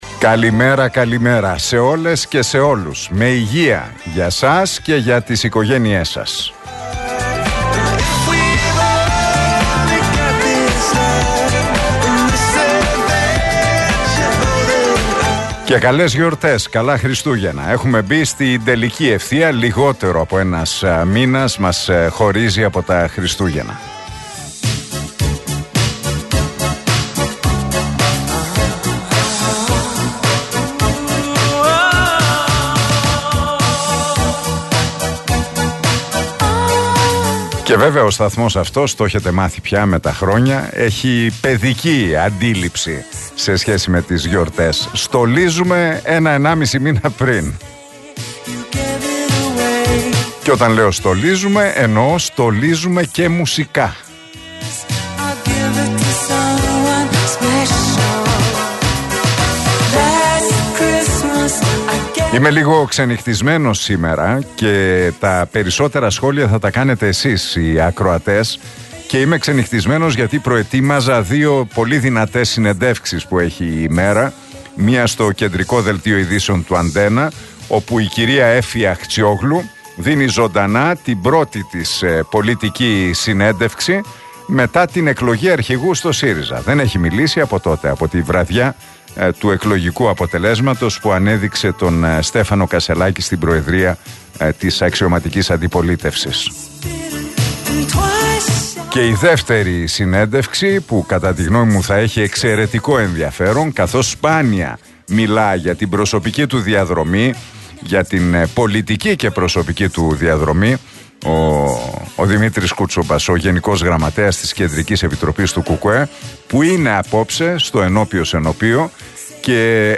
Ακούστε το σχόλιο του Νίκου Χατζηνικολάου στον RealFm 97,8, την Πέμπτη 30 Νοεμβρίου 2023.